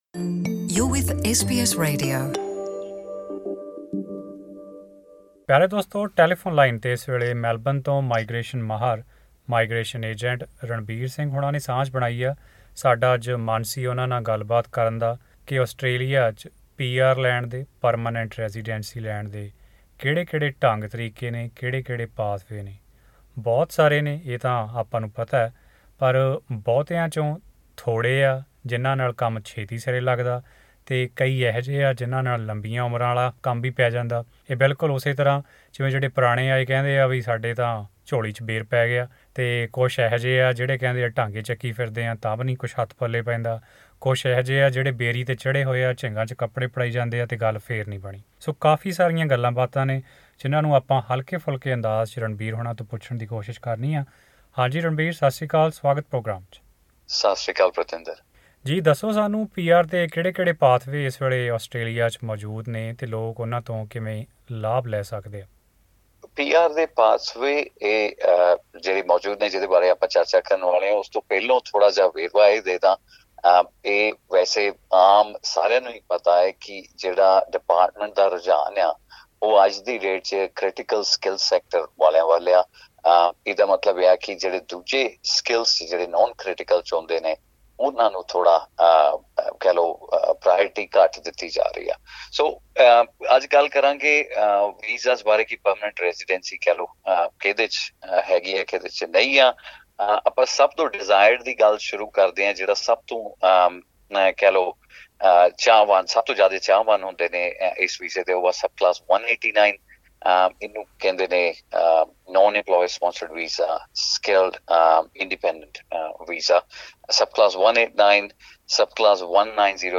ਜ਼ਿਆਦਾ ਜਾਣਕਾਰੀ ਲਈ ਮੈਲਬੌਰਨ ਦੇ ਮਾਈਗ੍ਰੇਸ਼ਨ ਏਜੇੰਟ੍ਸ ਨਾਲ਼ ਕੀਤੀ ਇਹ ਗੱਲਬਾਤ ਸੁਣੋ।